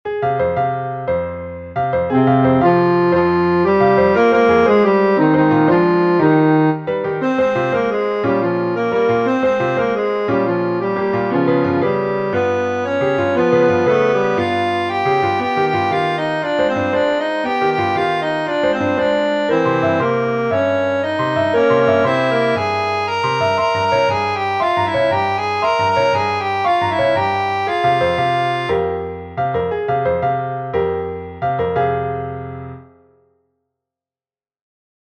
FF:HV_15b Collegium male choir